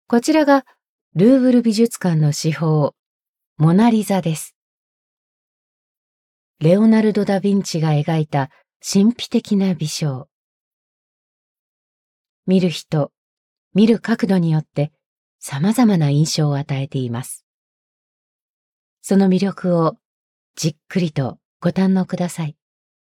Audioguides
Ihre strahlende, tiefe Stimme hat eine ausgeprägte Überzeugungskraft und einen vertrauenerweckenden Klang.